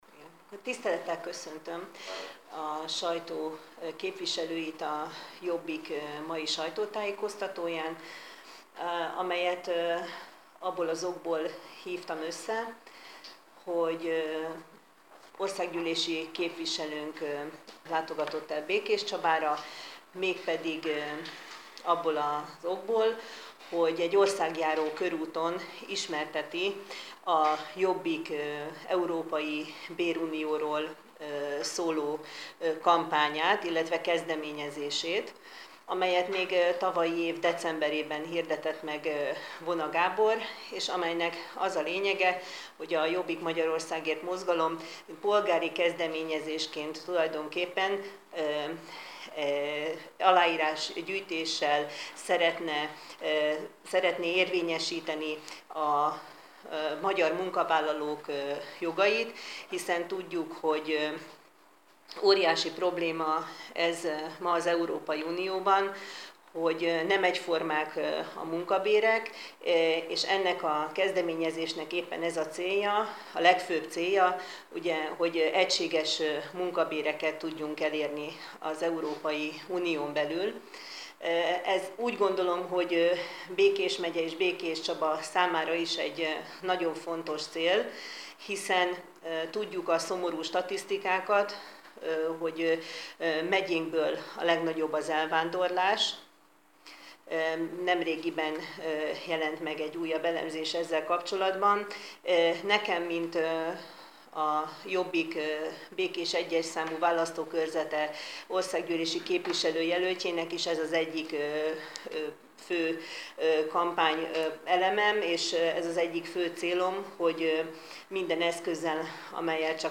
Békéscsabára látogatott Gyöngyösi Márton országgyűlési képviselő, ahol sajtótájékoztatón ismertette a Jobbik bérunió kezdeményezését, és annak jelenlegi állását.